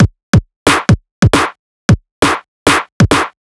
Clutta Beat 135.wav